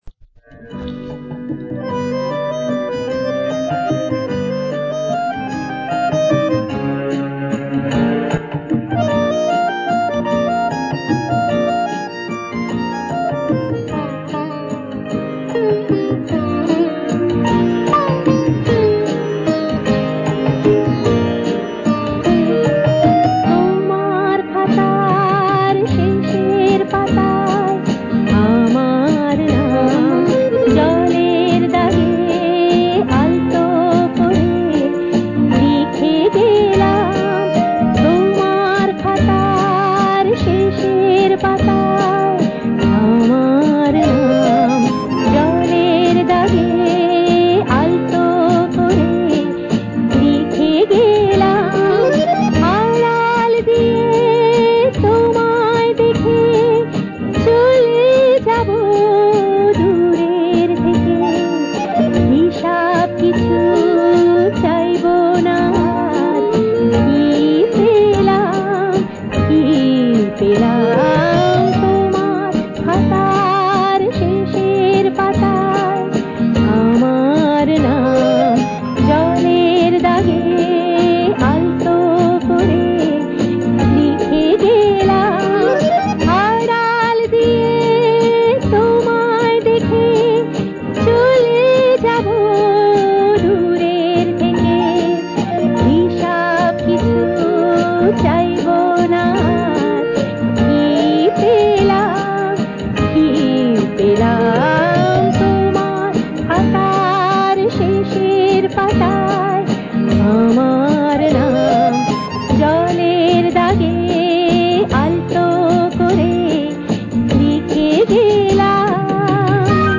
Bengali song recordings